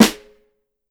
Band Snare.wav